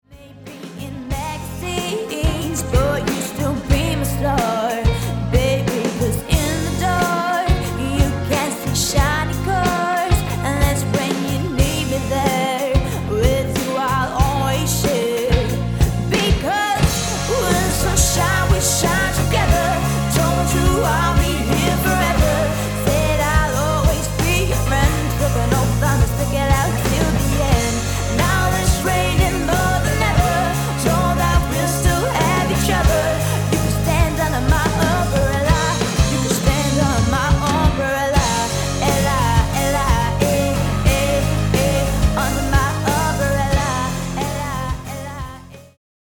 leverer dem som rock-sange